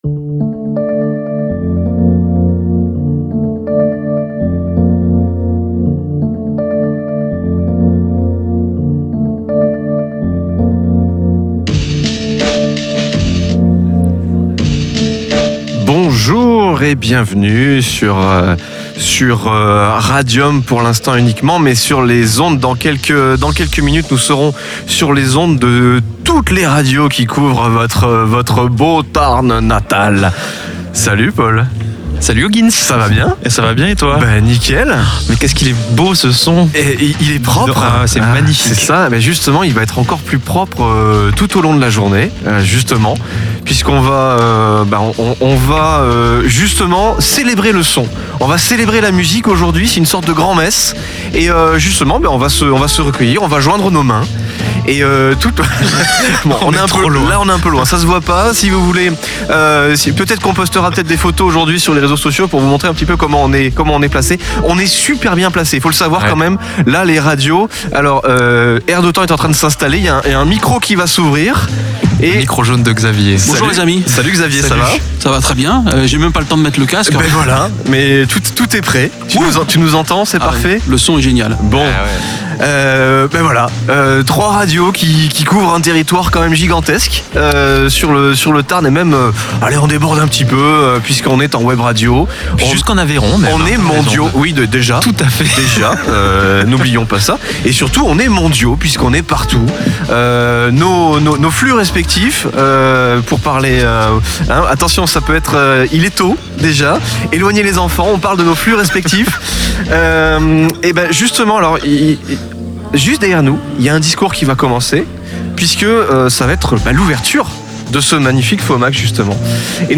Le FOMAC démarre en direct, avec le plateau des radios Radio Albigés, Radio R d'Antan et RADIOM.